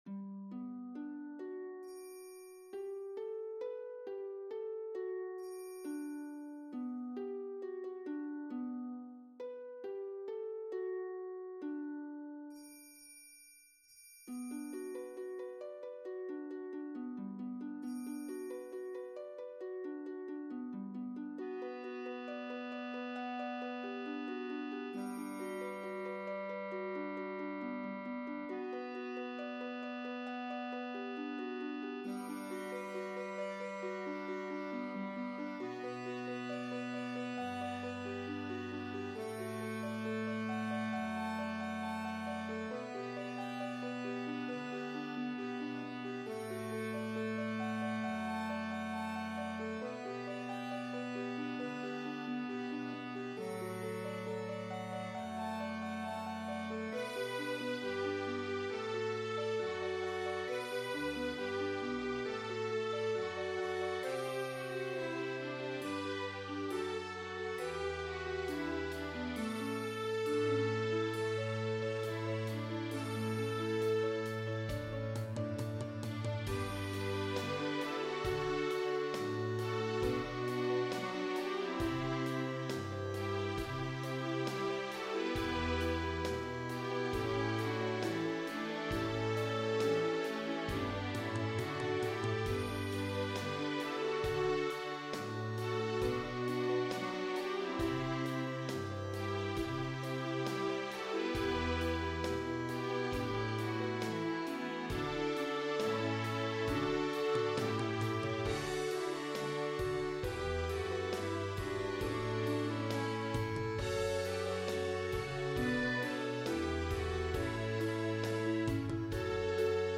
2. Langfassung (mit Intro und Zwischenteil/Tanz)
instrumental